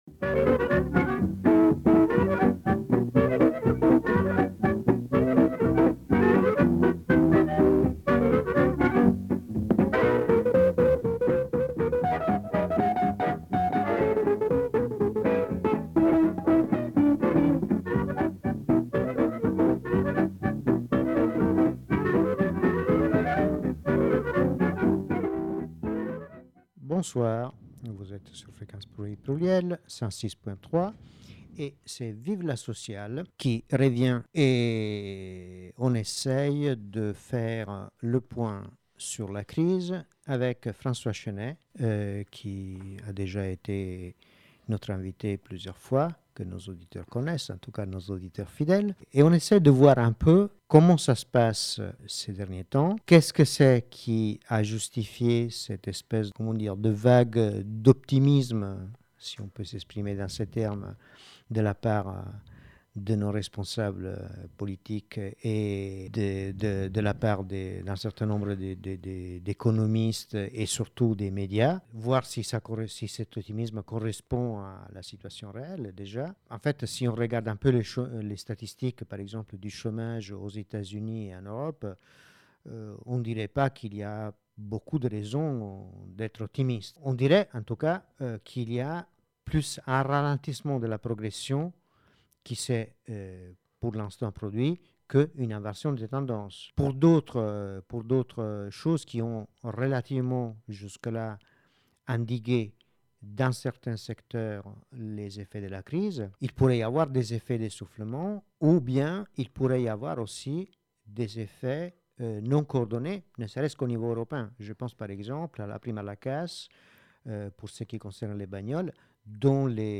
L’émission complète